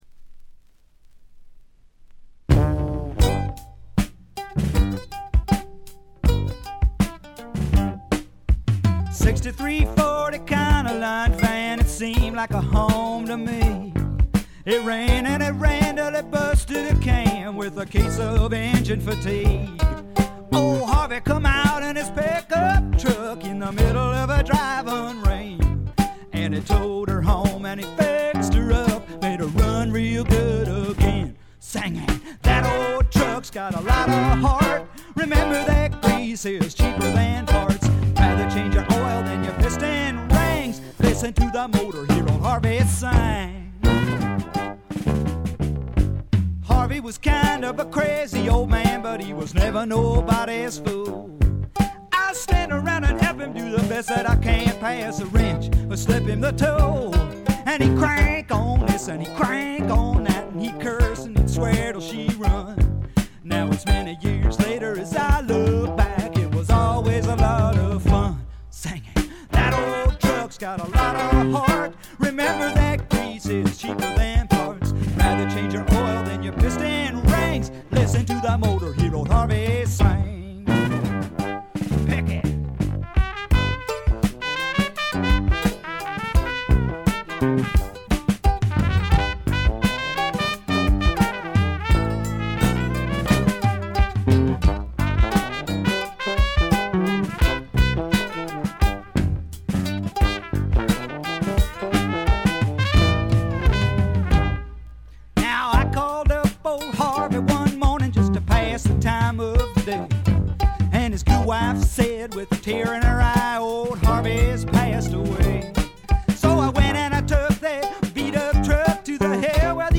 微細なバックグラウンドノイズがわずかに聴かれる程度。
いかにもカナダらしい清澄な空気感と薄味のルーツ系の味付けも心地よいですね。
試聴曲は現品からの取り込み音源です。
Guitar, Mandolin, Autoharp, Vocals